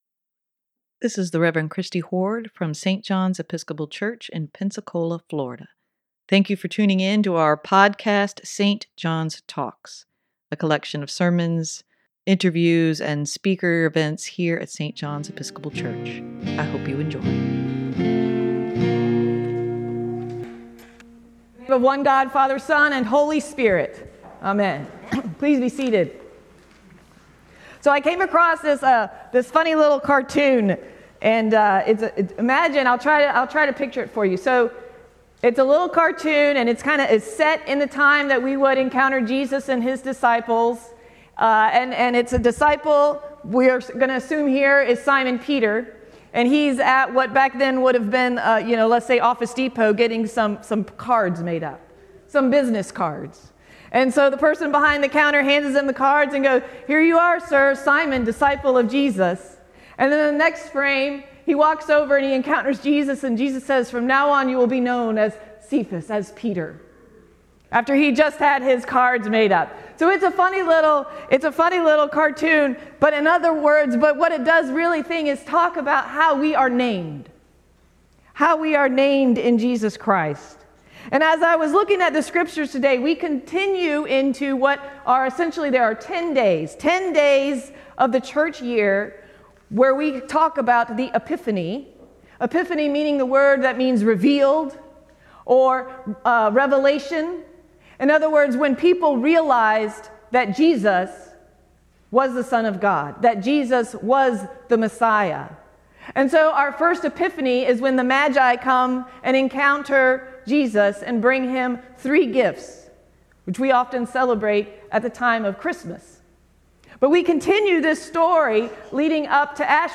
sermon-1-15-23.mp3